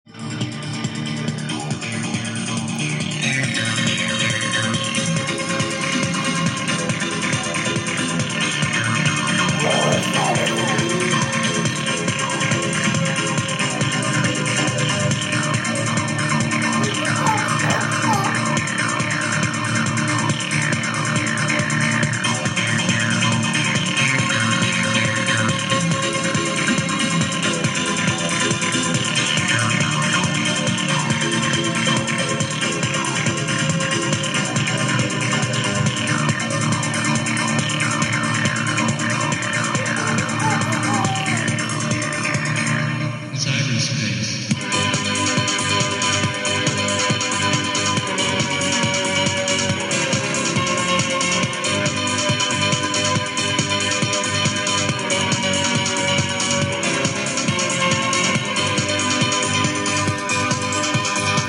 Zná někdo tyto Rave hudební skladby?